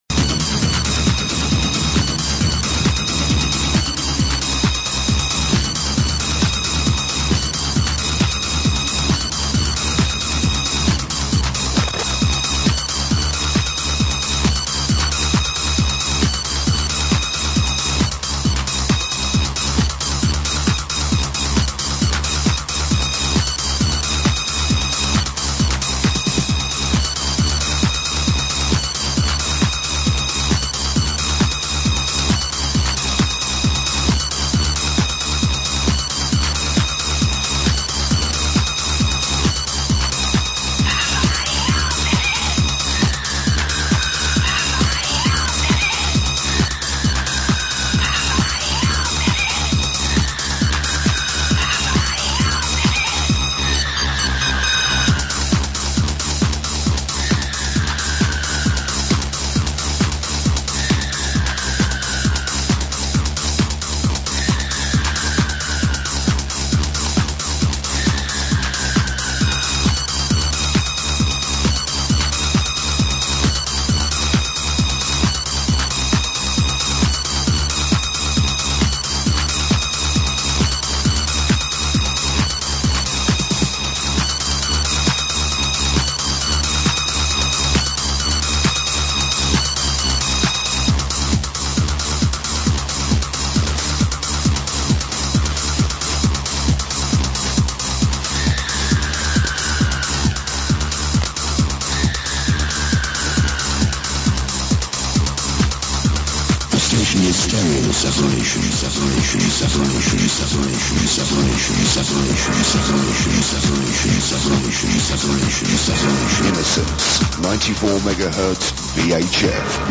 (quality a bit sus' on some tapes)
These tapes were recorded in Hammersmith, London and were some of the very first pirate radio stations I heard and recorded.
As with the Chillin' tapes, they had loads of adverts inbetween the music.